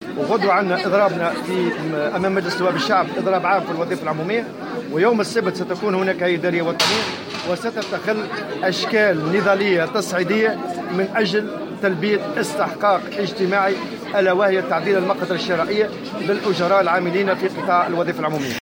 وأبرز الطبوبي ، في تصريح للجوهرة أف أم، على هامش زيارة، أداها اليوم الأربعاء إلى النفيضة للإشراف على إحياء الذكرى 68 لأحداث 21 نوفمبر 1950، إن المنظمة الشغيلة تنأى بنفسها عن الاتهامات التي توجهها لها أطراف سياسية معيّنة تعمل على شيطنة الاتحاد عبر مواقع التواصل الاجتماعي، مشددا على أن الاتحاد يخوض معركة وطنية من أجل ضمان استقرارية القرار الوطني، بحسب تعبيره.